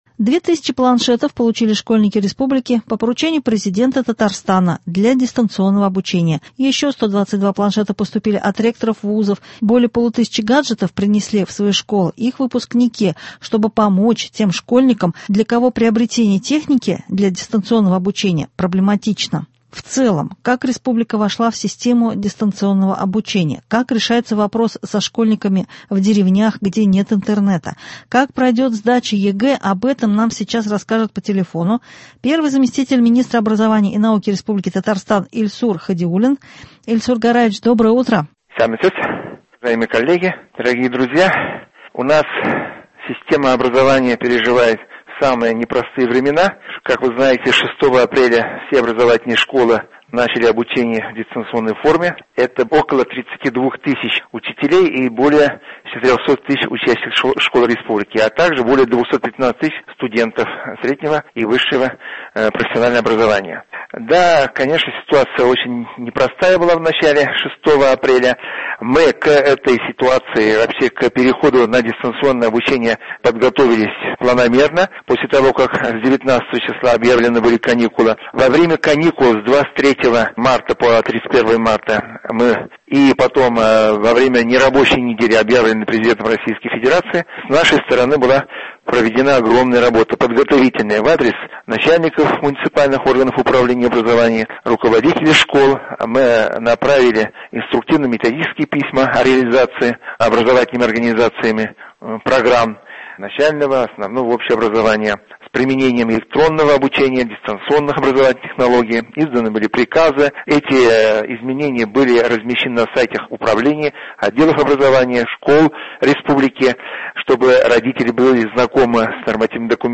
В целом как республика вошла в систему дистанционного обучения, как решается вопрос со школьниками в деревнях, где нет интернета, и как пройдет сдача ЕГЭ – об этом нам сейчас расскажет по телефону первый заместитель министра образования и науки РТ Ильсур Хадиуллин